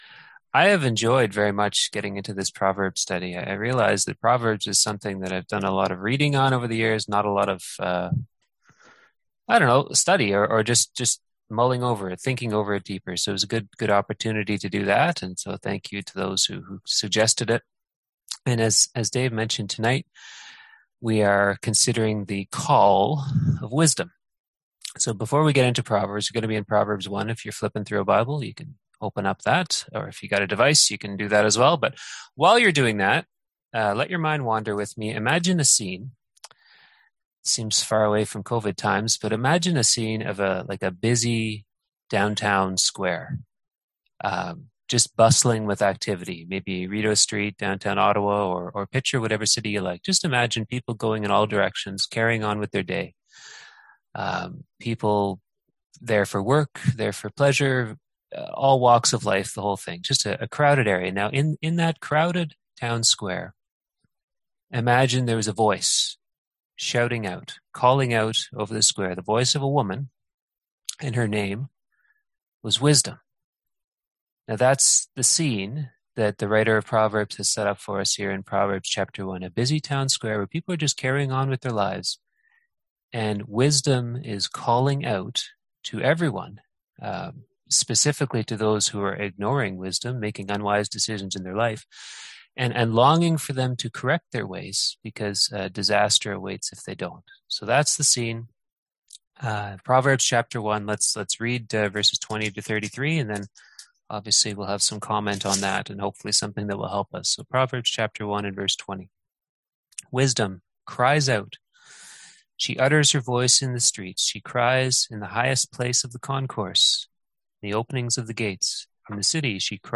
Passage: Proverbs 1:20-33, Proverbs 8:1-11 Service Type: Seminar